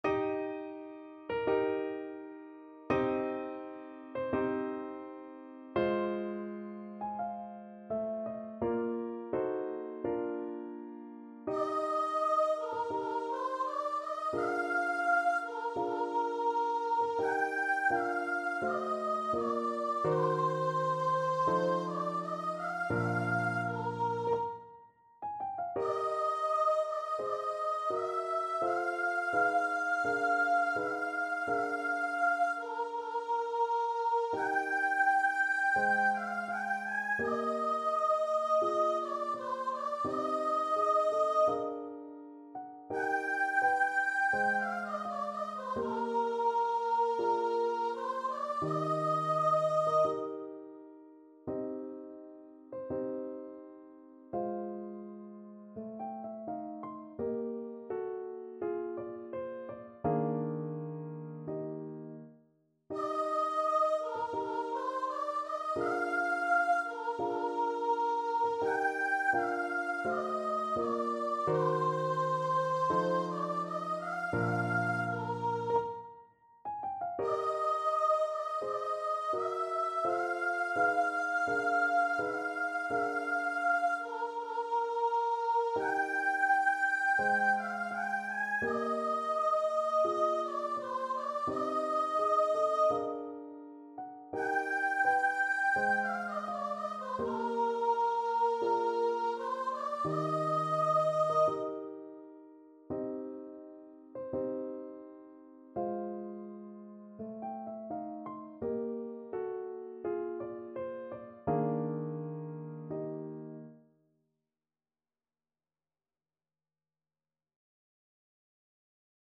Free Sheet music for Voice
Eb major (Sounding Pitch) (View more Eb major Music for Voice )
~ = 42 Sehr langsam
2/4 (View more 2/4 Music)
Classical (View more Classical Voice Music)